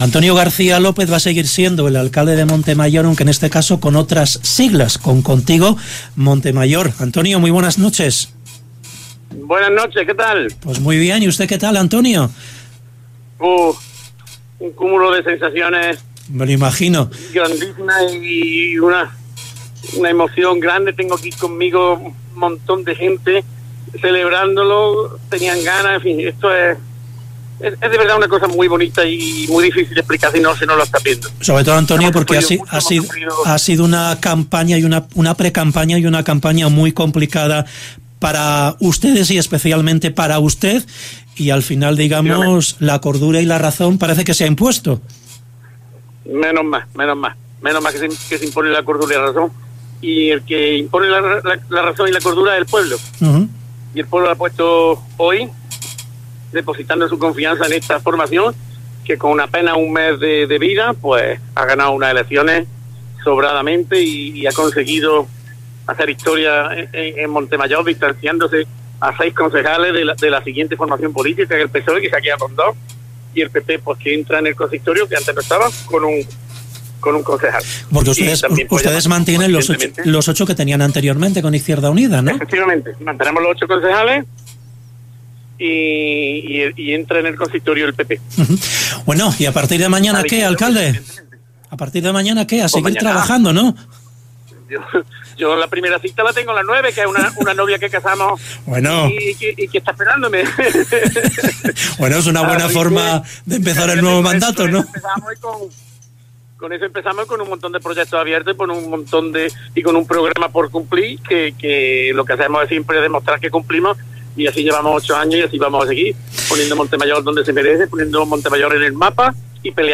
Entrevista 28M